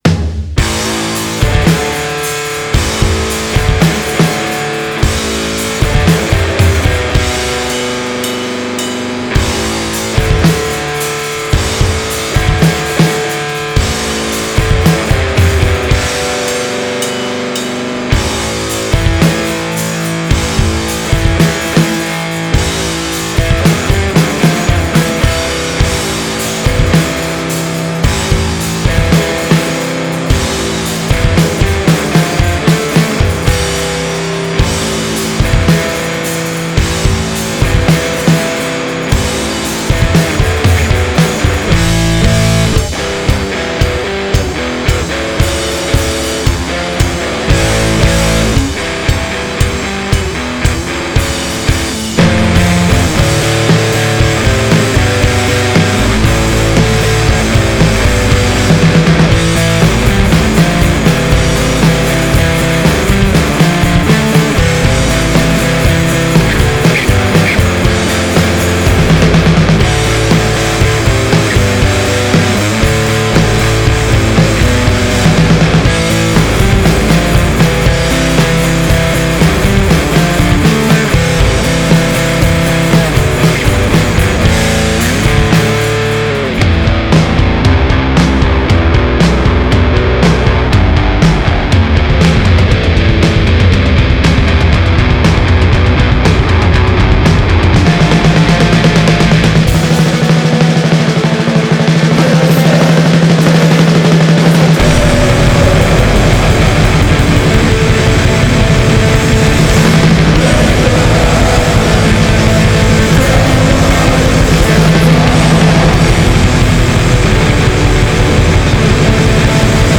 7" split
Black Metal meet Stonerpunk!